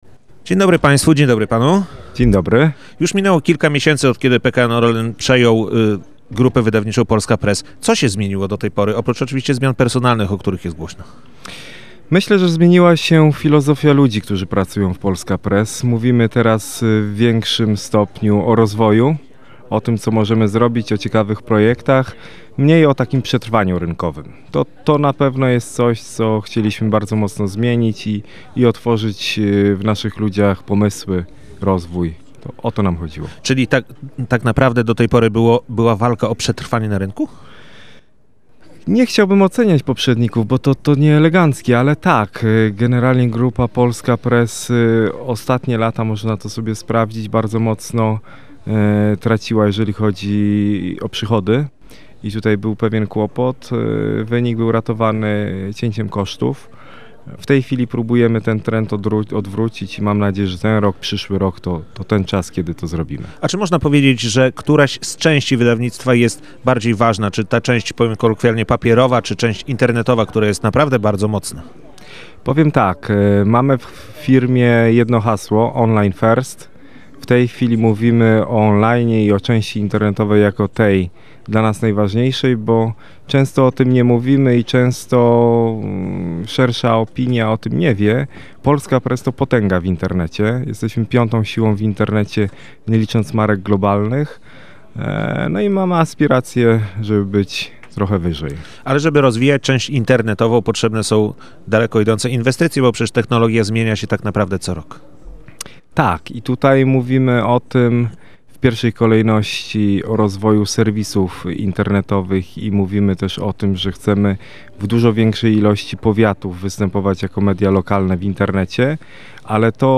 Rozmowa odbywa się w czasie trwającej w gdyni IV edycji Forum Wizja Rozwoju.